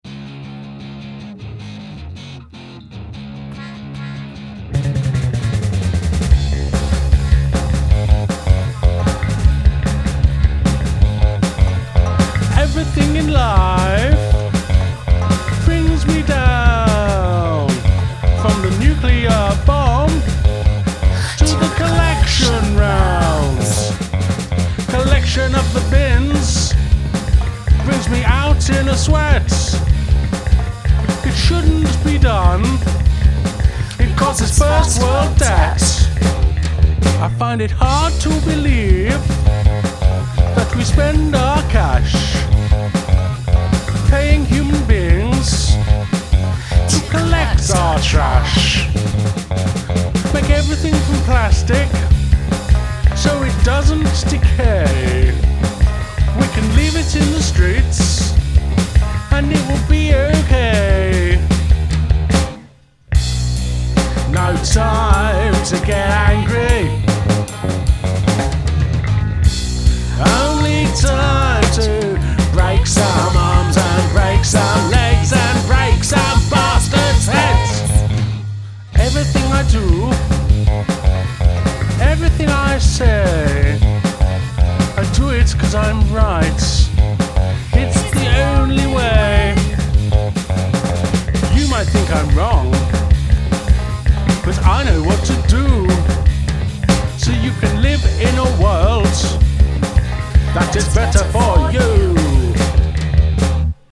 On-line collaborations continued from last year.
guitar
lyrics, vocals, drums, bass, production
backing vocals, banjo, accordion
Catchy, quirky,  lo fi - I was smiling all the way through!
Great fun, love the accent on the vocals!